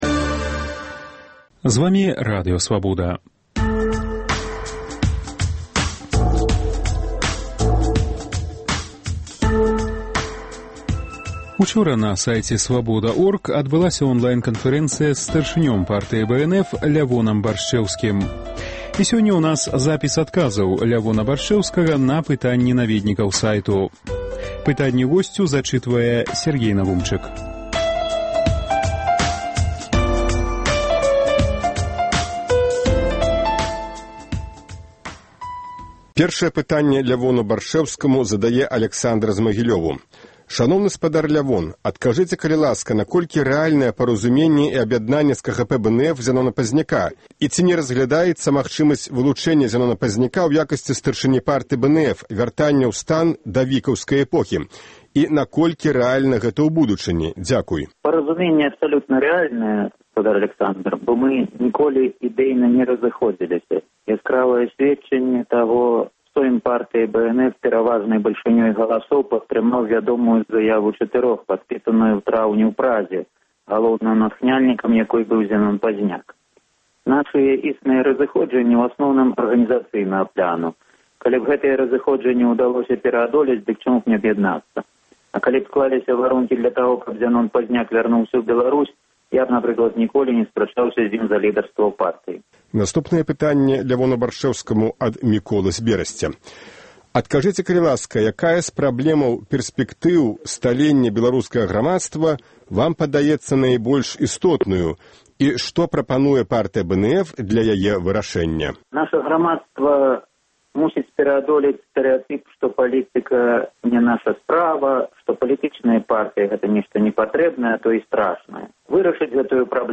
Радыёварыянт онлайн-канфэрэнцыі